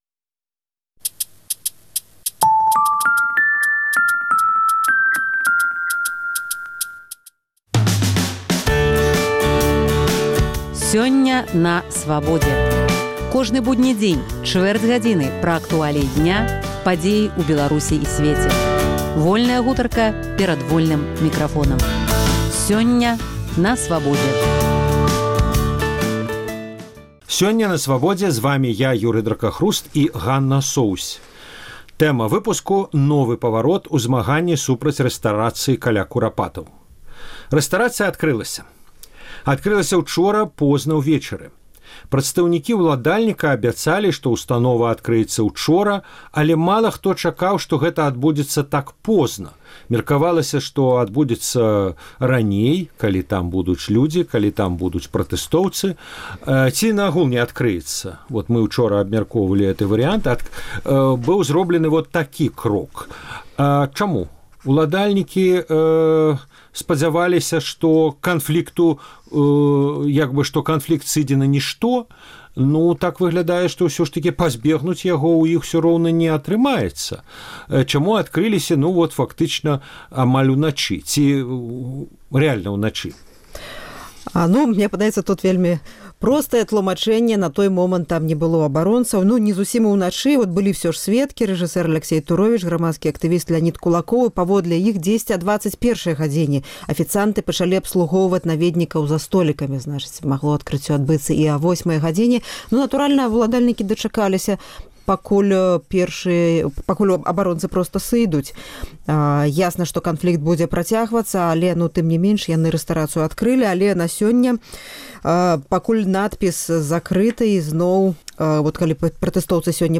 Размова